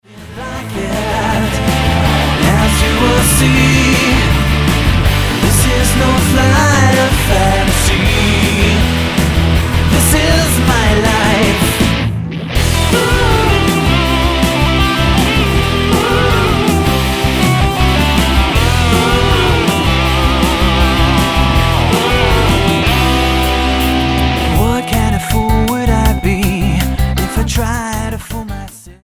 STYLE: Rock
The overall mix is pleasant on the ear and well balanced.